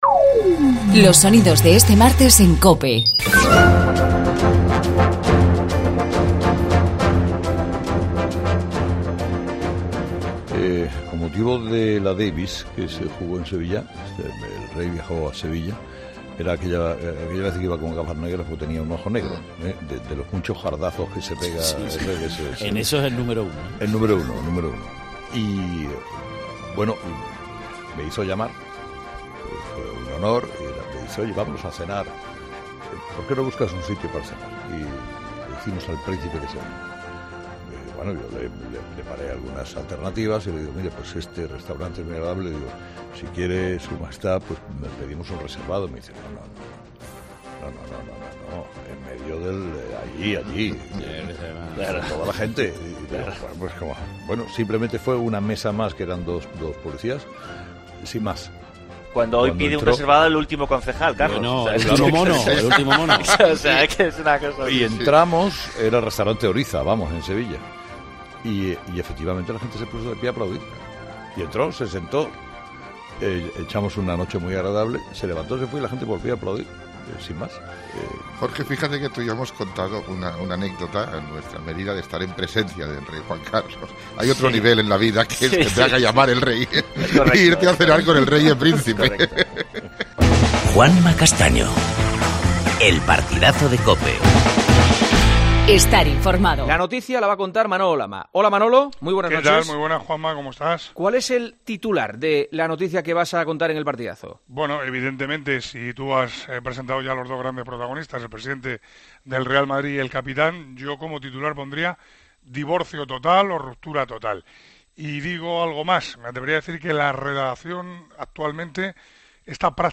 Repasamos lo mejor del día en tu radio en las entrevistas y reportajes de 'Herrera', 'La Linterna', 'Mediodía' y Deportes COPE